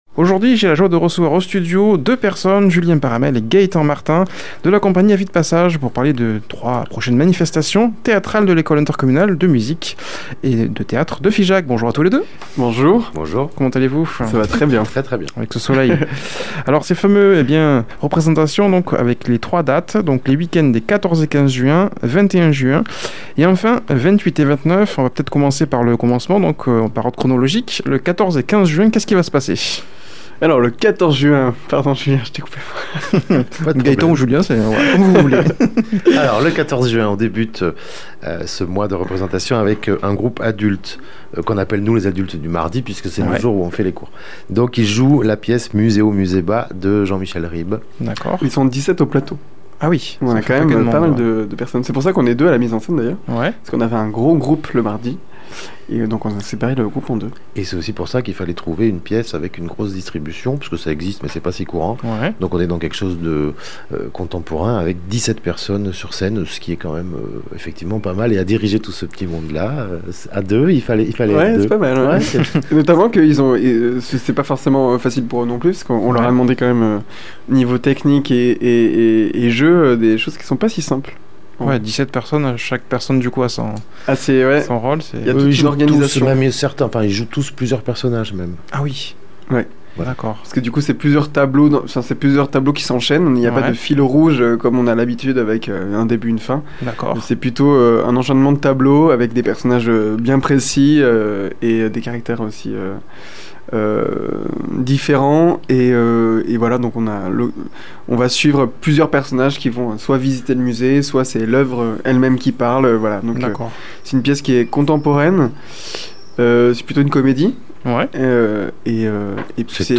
invités au studio